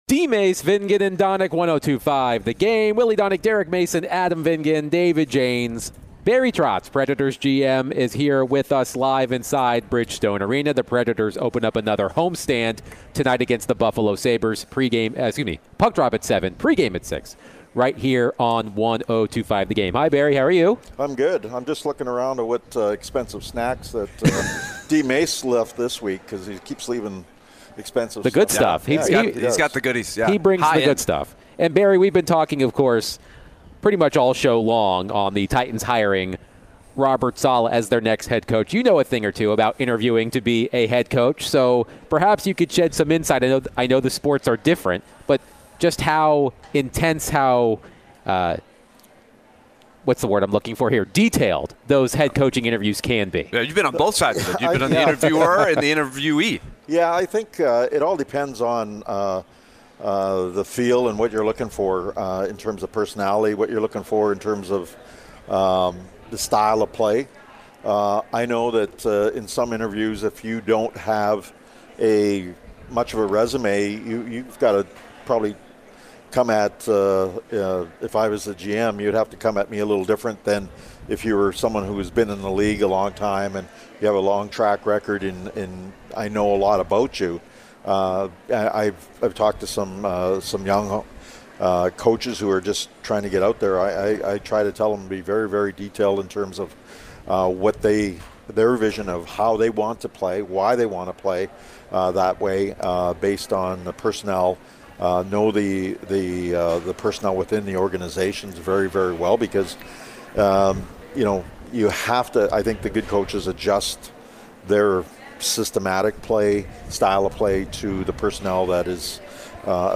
Nashville Predators General Manager Barry Trotz joined DVD for his weekly chat